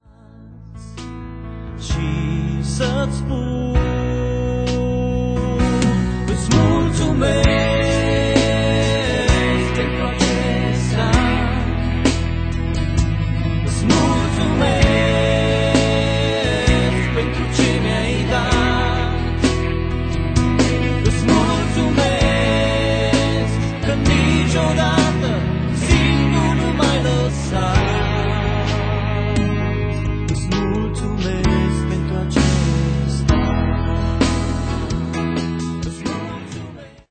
si vocile de exceptie.